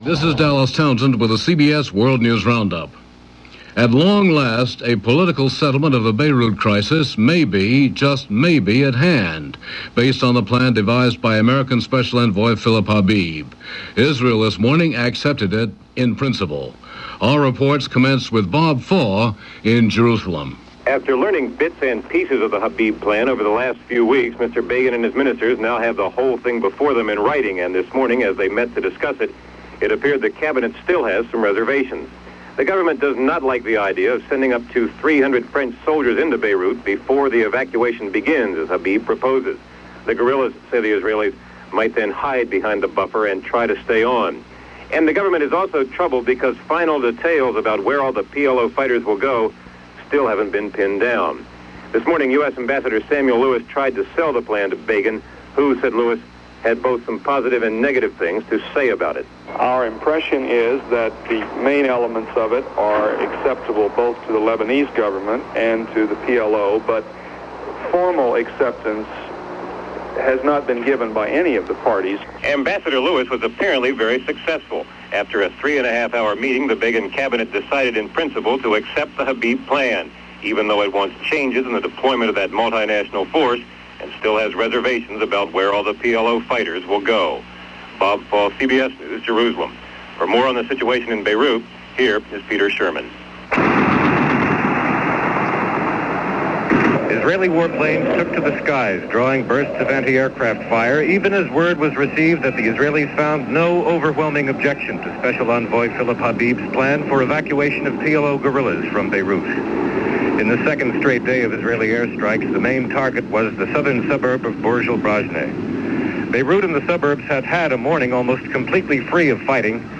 CBS World News Roundup – 9:00 am Network News